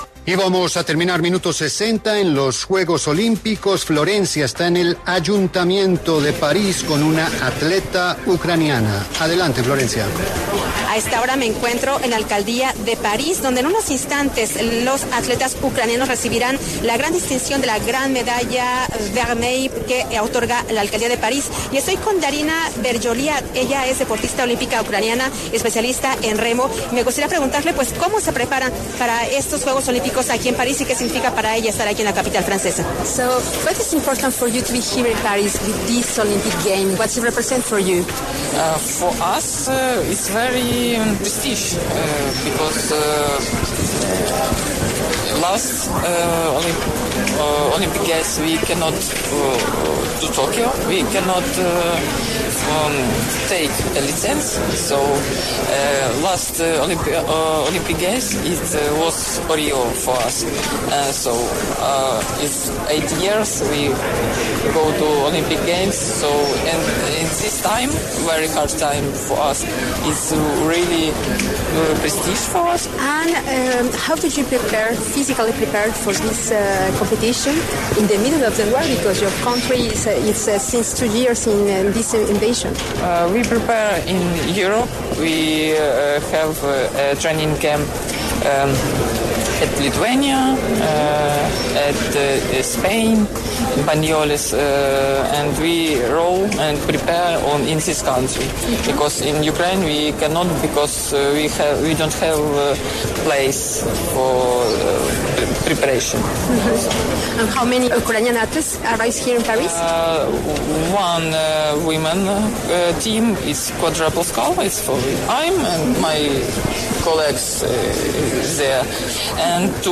conversó con La W acerca de la preparación para estas justas deportivas.